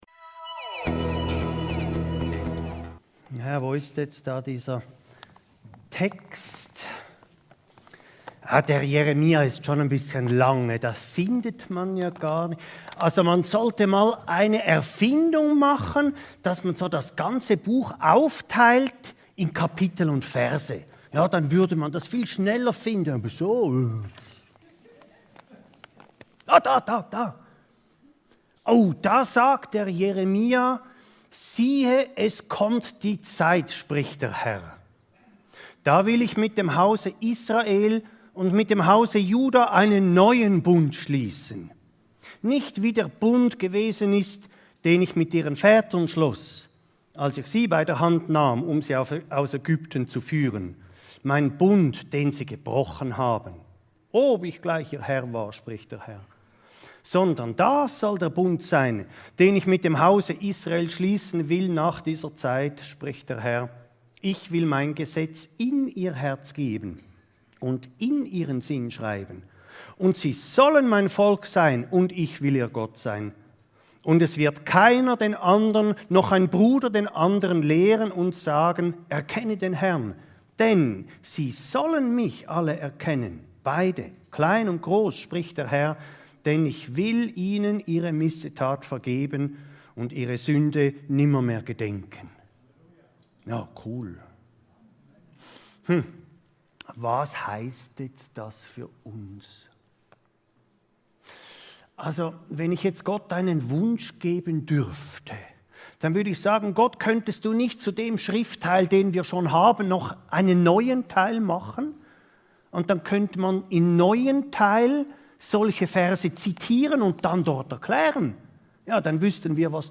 Vorbilder prägen ~ Your Weekly Bible Study (Predigten) Podcast